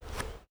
Quarterback Throw Distant.wav